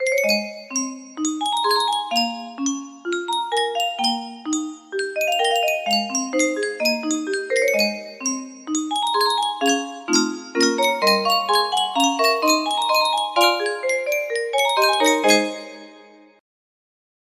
Yunsheng Music Box - By the Light of the Silvery Moon 4211 music box melody
Yunsheng Music Box - By the Light of the Silvery Moon 4211
Full range 60